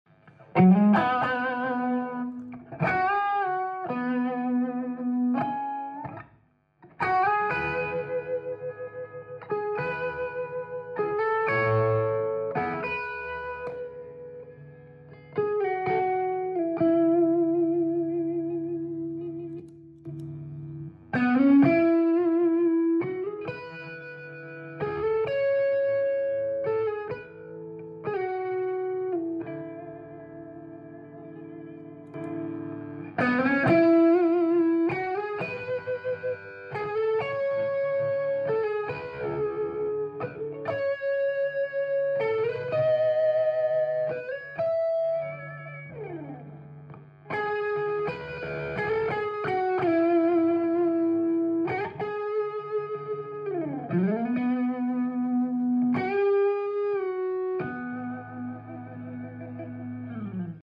Demo sound pedal Browne Amplification sound effects free download
Demo sound pedal Browne Amplification Protein dengan karakter Blues Breaker dan ODR-1.